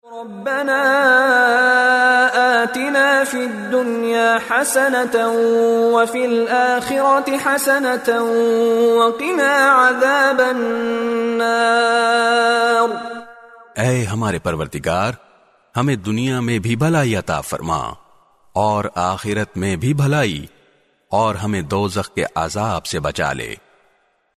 by Mishary Rashid Alafasy
His melodious voice and impeccable tajweed are perfect for any student of Quran looking to learn the correct recitation of the holy book.
rabbana atina fid dunya hasanah full dua with urdu translation.mp3